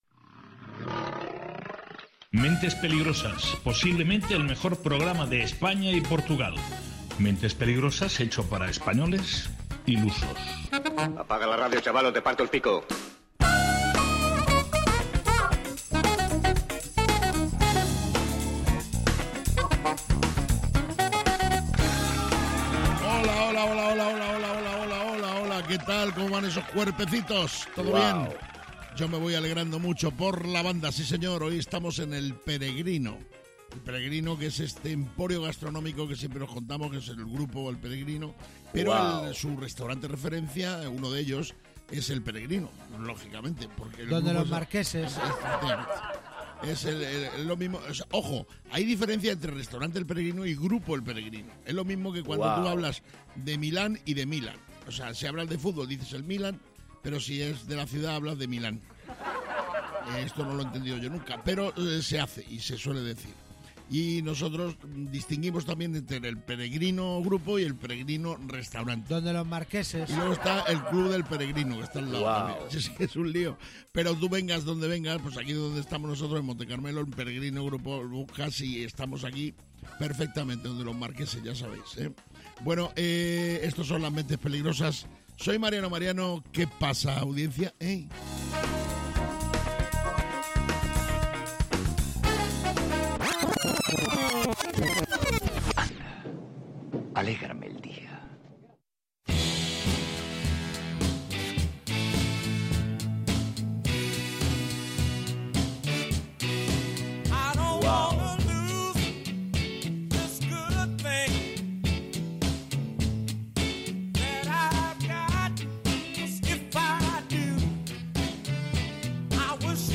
Mentes Peligrosas es un programa de radio, esto sí lo tenemos claro, lo que no está tan claro es qué pasará en cada una de sus entregas, no lo saben ni los que lo hacen, ni sus propios entornos. Mentes Peligrosas es humor, y quizás os preguntaréis, ¿y de qué tipo de humor es?, pues del que te ríes, porque si no, podría ser una bicicleta, un destornillador, cualquier cosa.